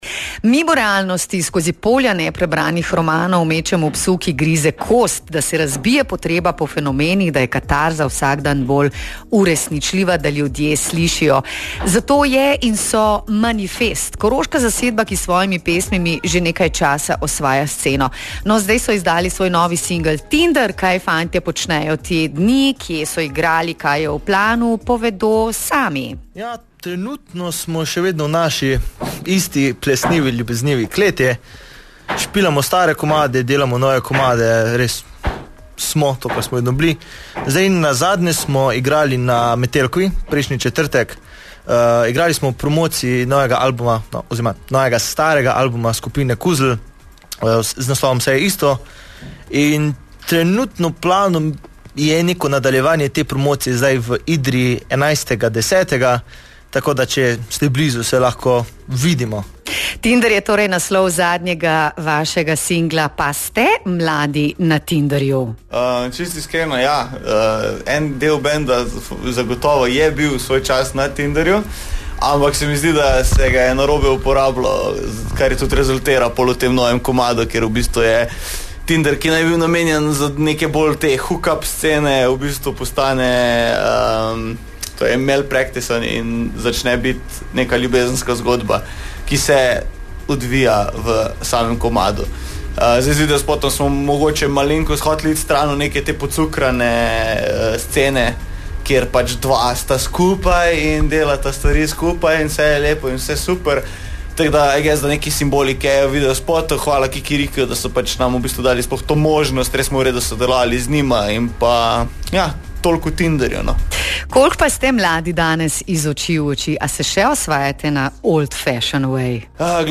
Manifest, koroški hard rock/punk band imaj novo pesem.
Ker so znani po svojih družbeno kritičnih besedilih, ocenjujeo trenutno stanje v državi. Poslušajte kratek pogovor in njihovo novo pesem Tindr.